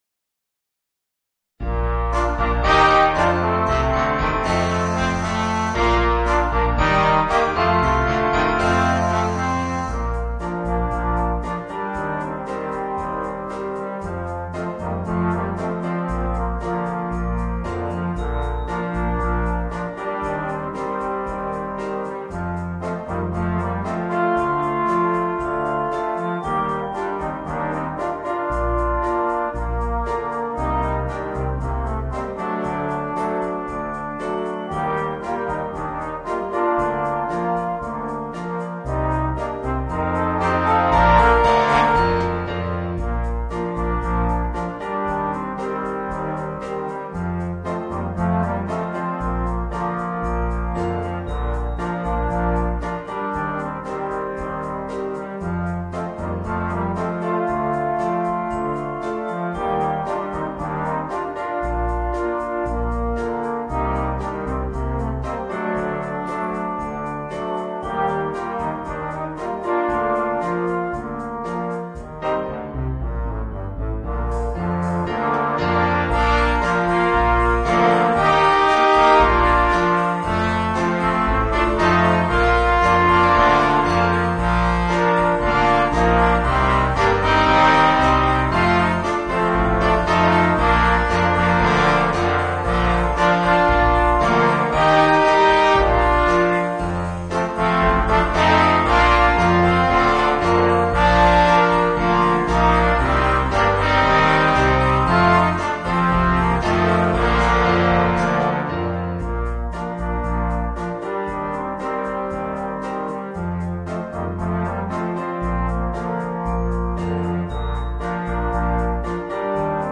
Voicing: 4 Trombones and Piano